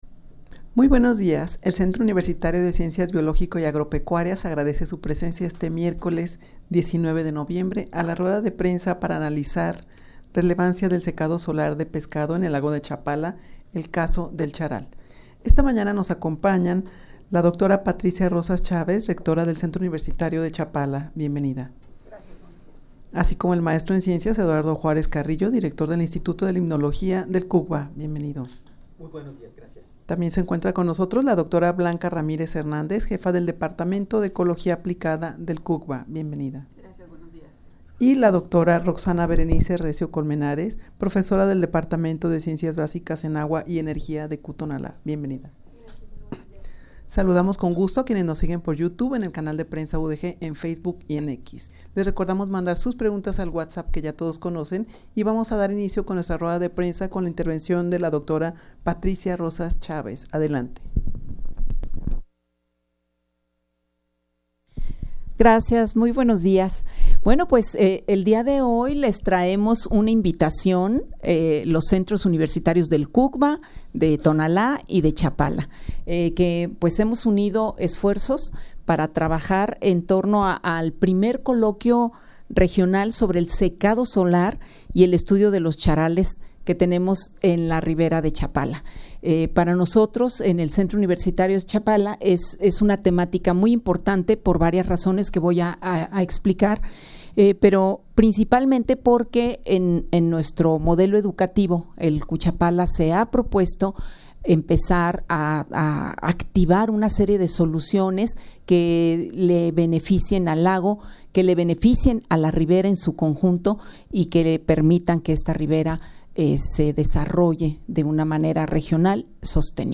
Audio de la Rueda de Prensa
rueda-de-prensa-para-analizar-relevancia-del-secado-solar-de-pescado-en-el-lago-de-chapala-el-caso-del-charal.mp3